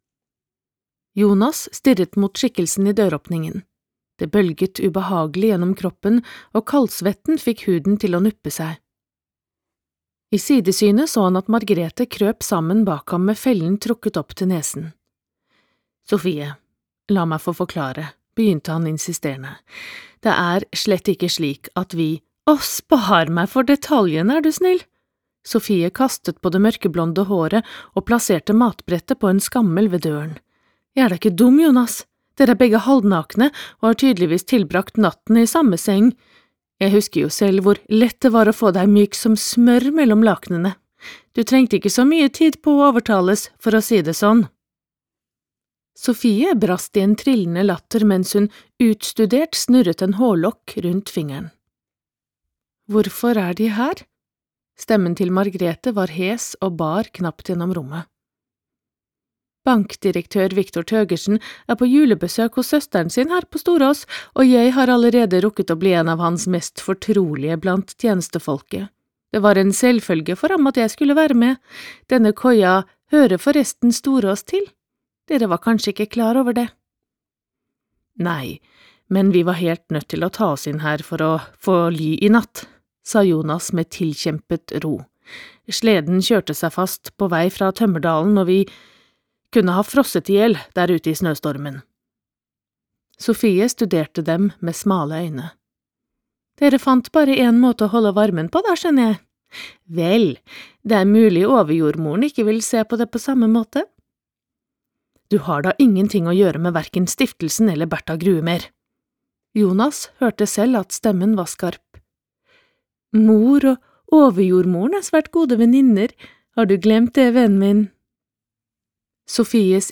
Utdraget er hentet fra lydbokbokutgaven